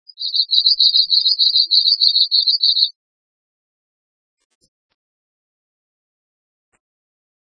〔メボソムシクイ〕リュリュッ／チョチョリチョチョリ（さえずり）／亜高山などの針
mebosomusikui.mp3